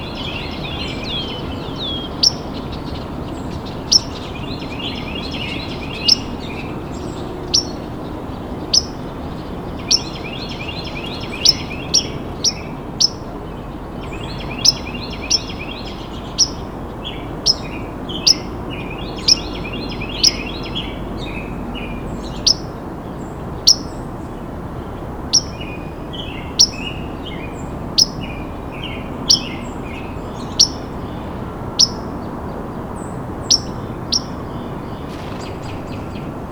As I walked by the tall hedge next to our porch, the bird called out. I had my recorder and shotgun mic at the ready, so I recorded its calls.
white-throated-sparrow.wav